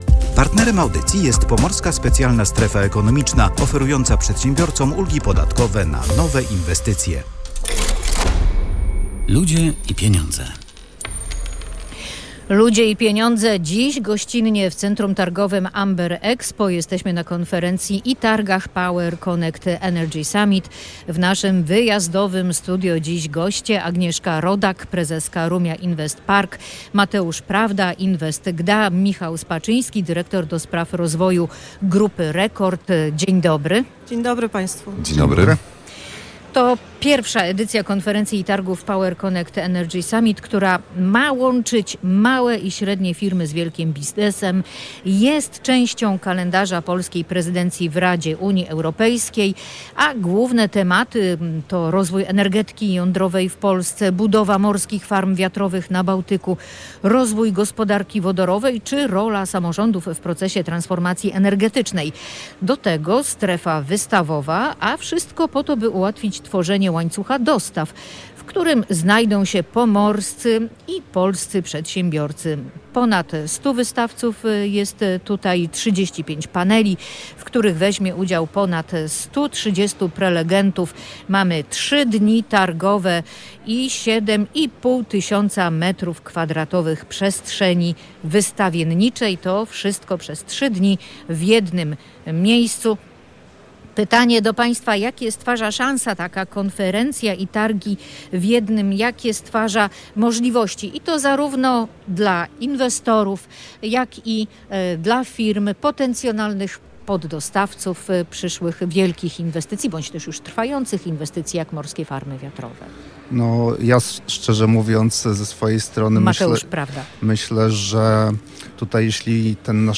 Ponad 100 wystawców, 35 paneli, w których bierze udział ponad 130 prelegentów – to wszystko w ramach trwającej w Gdańsku konferencji połączonej z targami PowerConnect Energy Summit.